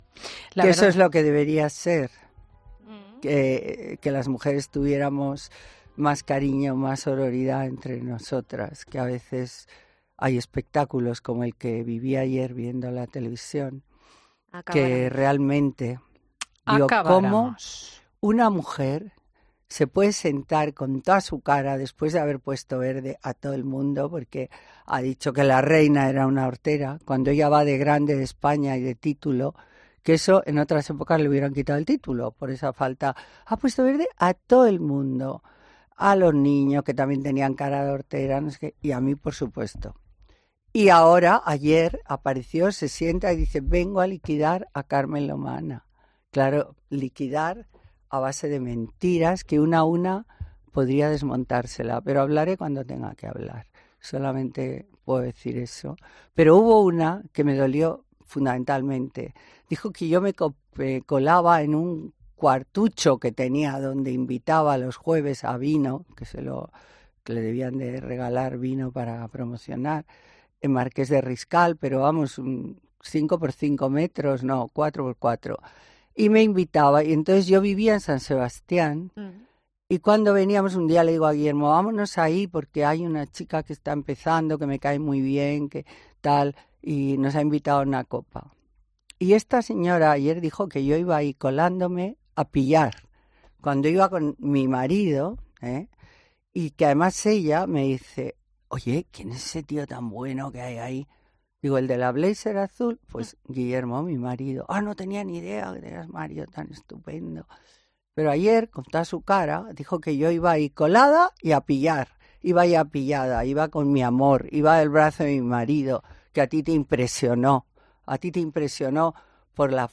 Por supuesto, te hablamos de Carmen Lomana, que ha venido a Fin de Semana para contarnos lo mejor de la semana.